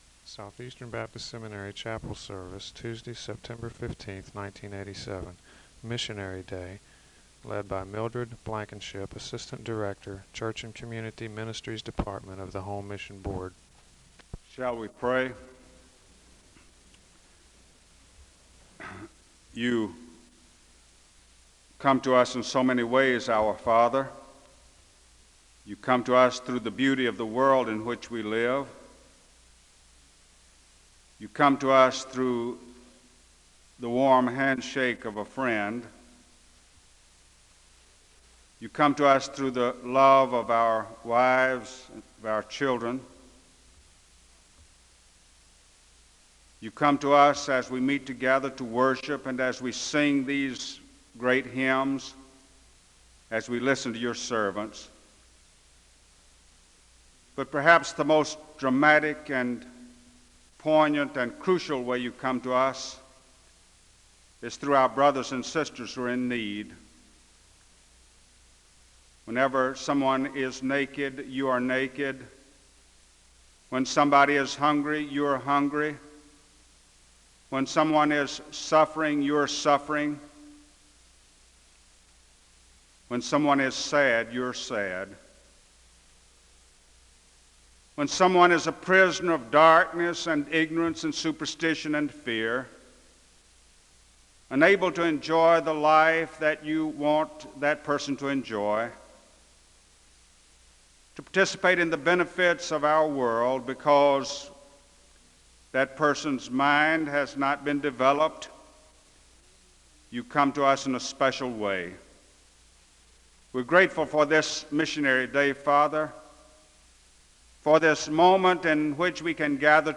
The service begins with a moment of prayer (0:00-2:49). The choir sings a song of worship (2:50-4:27).